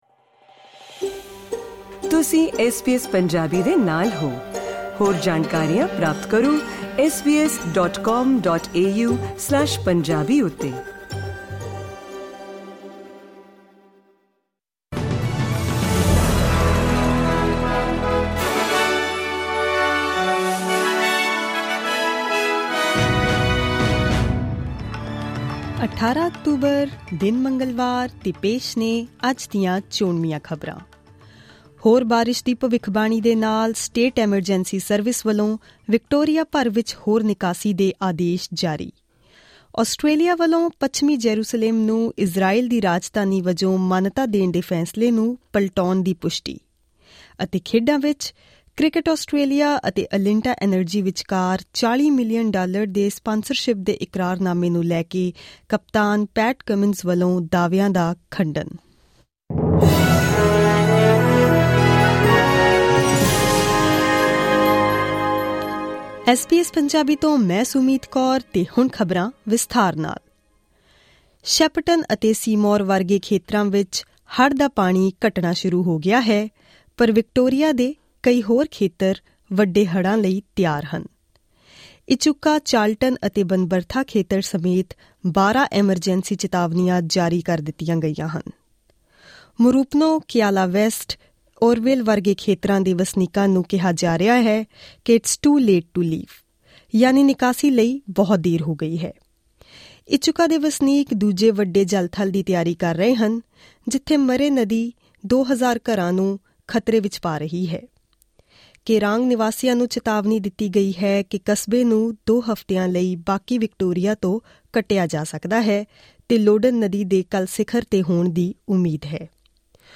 Click on the player at the top of the page to listen to this news bulletin in Punjabi.